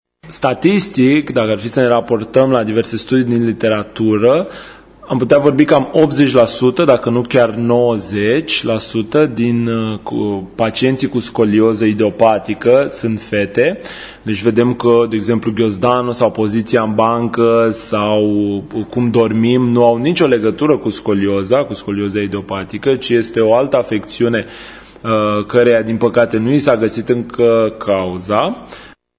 kinetoterapeut